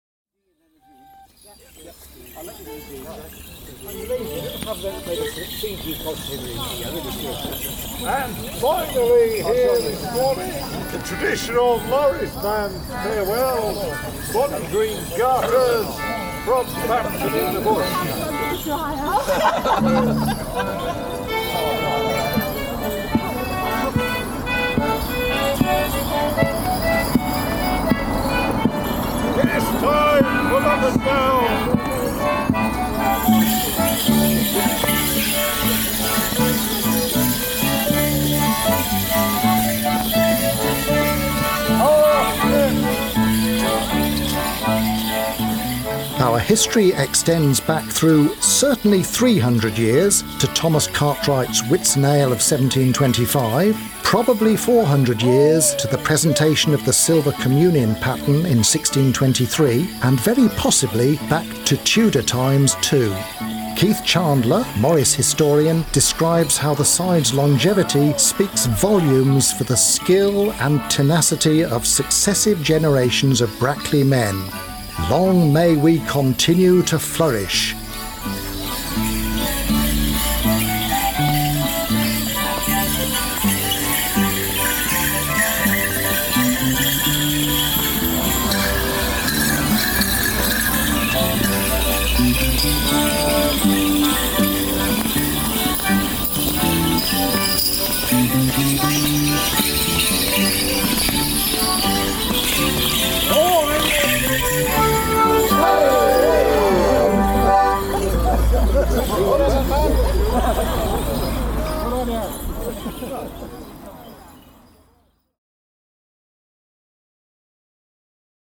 Recorded on the market place in Brackley on May Day morning 2013 with our friends from the Northampton Morris Men, and Royal Oak Morris, Eydon.